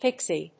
/píksi(米国英語)/